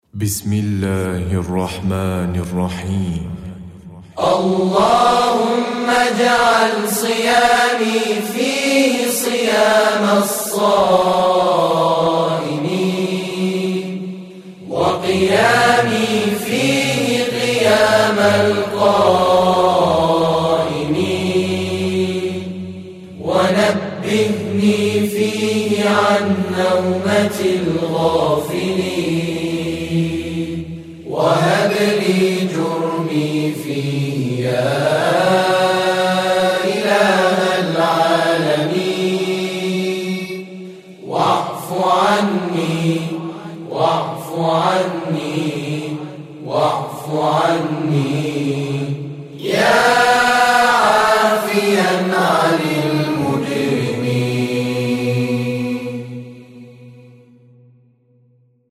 نماهنگ و سرود رسمی و معنوی